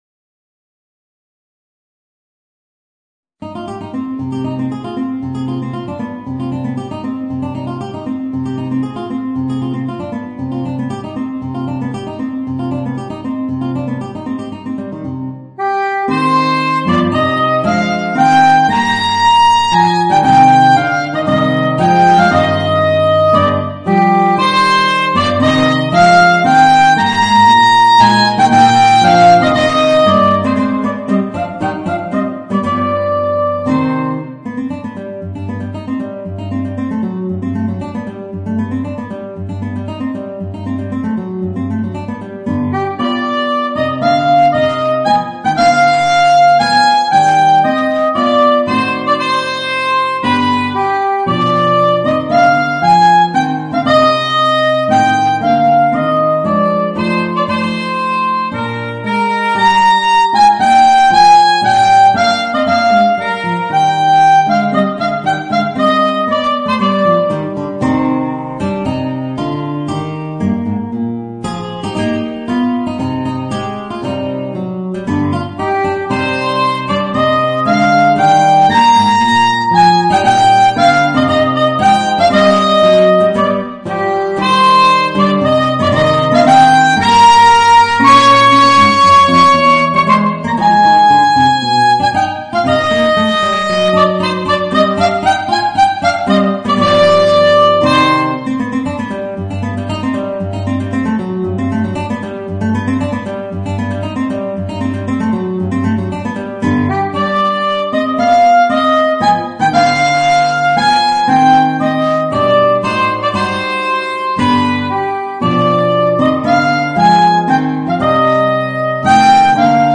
Voicing: Guitar and Soprano Saxophone